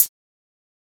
Closed Hats
HiHat (25).wav